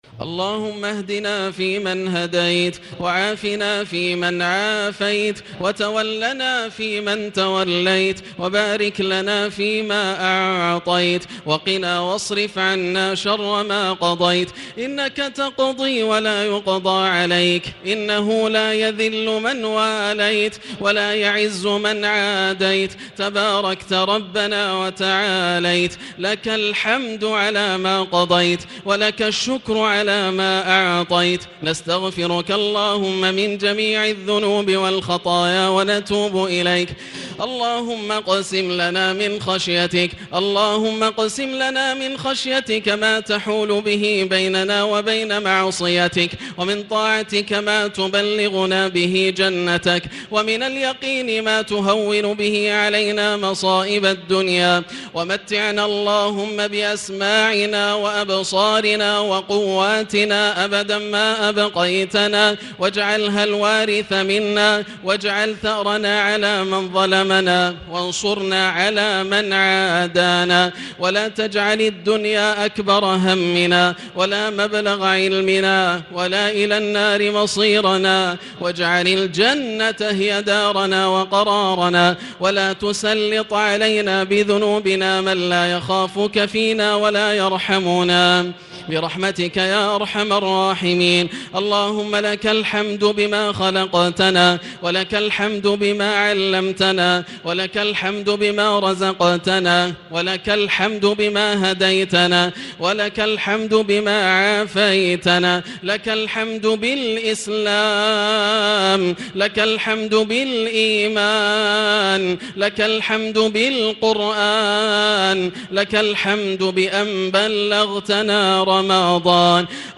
دعاء القنوت ليلة 2 رمضان 1440هـ | Dua for the night of 2 Ramadan 1440H > تراويح الحرم المكي عام 1440 🕋 > التراويح - تلاوات الحرمين